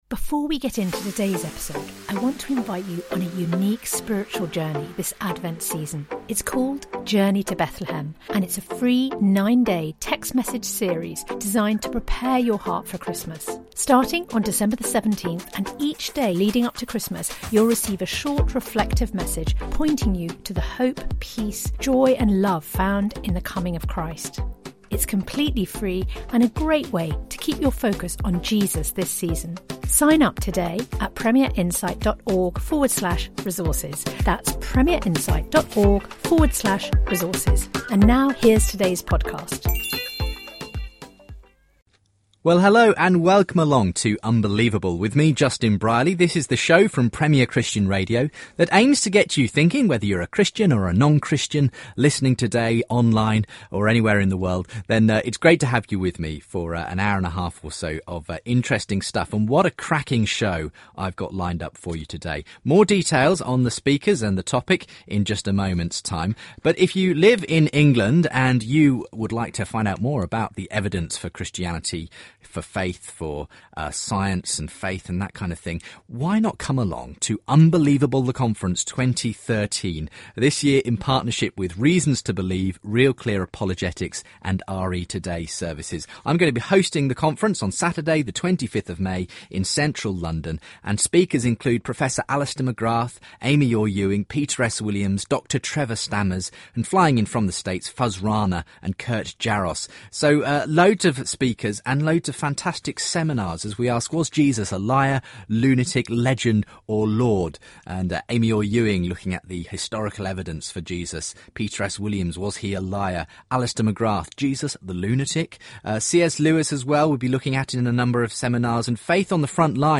In a busy show they debate The Argument from Fine Tuning, The Cosmological Arguement and The Moral Argument.